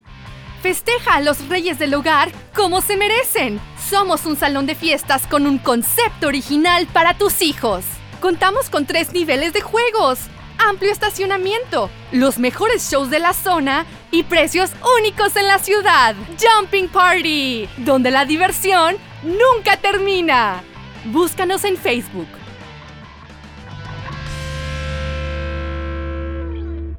Spanish (Mexico) and English (USA) female speaker.
Sprechprobe: Werbung (Muttersprache):
comercial.mp3